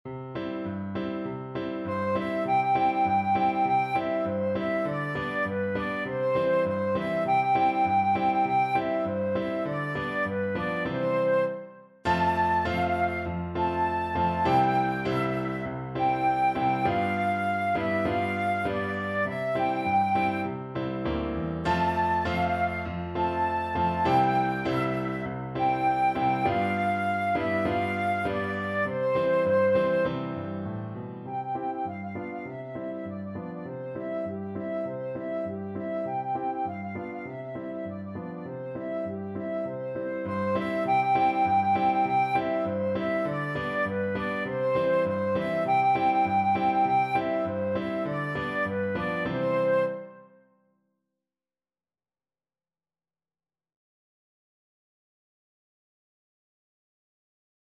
Traditional Trad. Ging Gang Goolie Flute version
Flute
2/4 (View more 2/4 Music)
C major (Sounding Pitch) (View more C major Music for Flute )
~ = 100 Allegro (View more music marked Allegro)
Traditional (View more Traditional Flute Music)